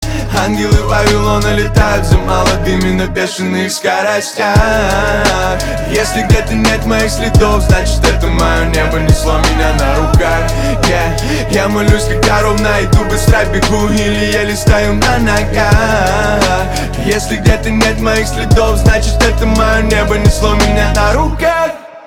русский рэп , грустные